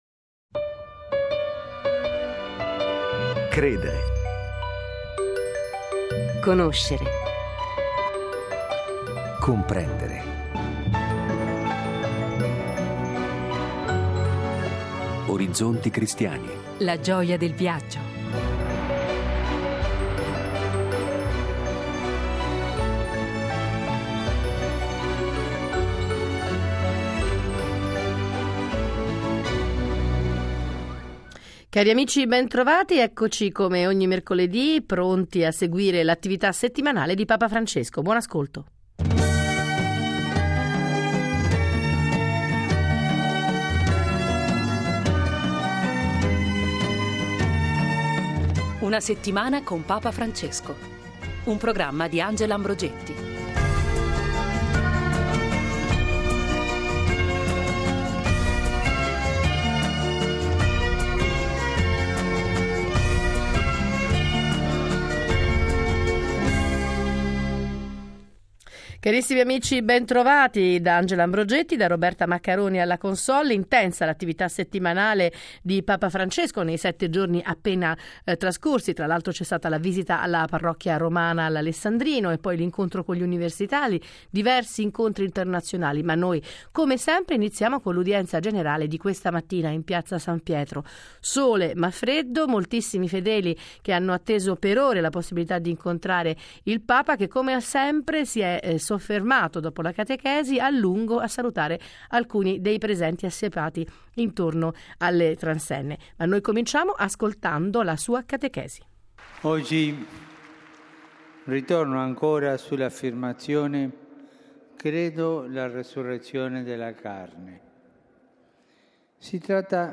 mercoledì 04 mercoledì Venticinque minuti ogni settimana per riascoltare i discorsi, rivivere gli incontri, raccontare le visite di Papa Francesco. Qualche ospite e la lettura dei giornali, ma soprattutto la voce del Papa a partire dall’appuntamento del mercoledì per l’Udienza Generale.